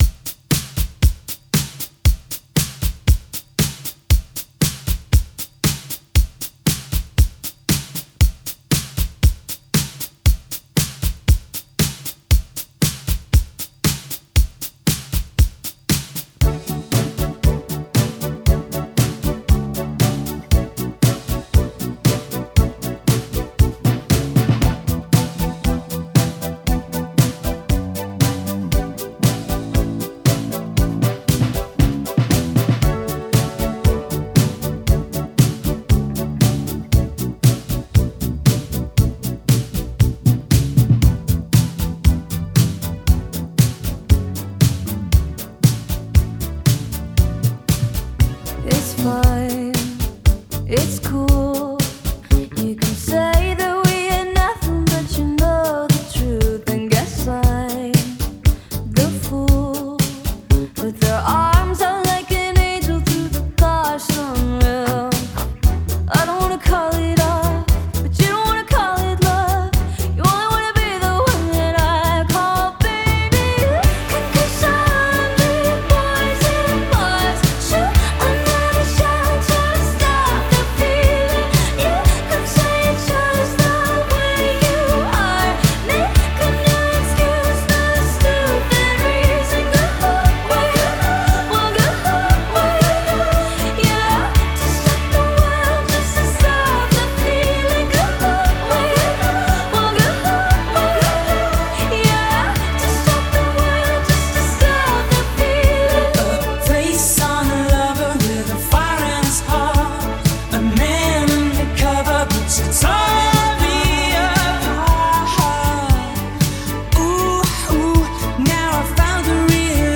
2024 Pop / Holiday 117